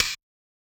Index of /musicradar/ultimate-hihat-samples/Hits/ElectroHat B
UHH_ElectroHatB_Hit-17.wav